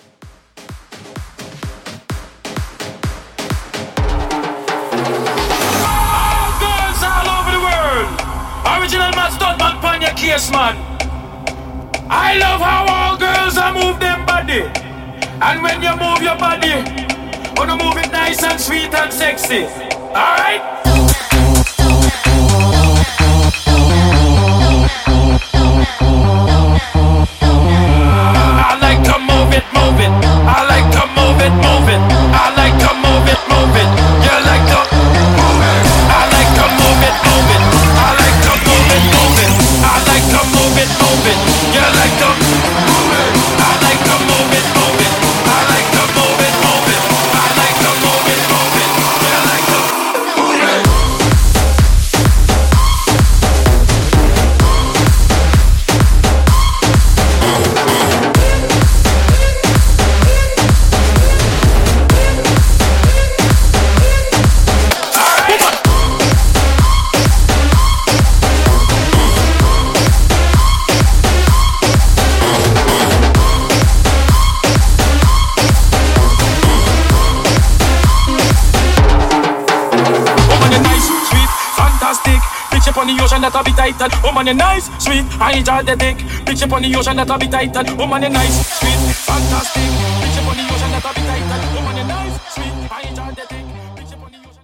Genre: BOOTLEG Version: Clean BPM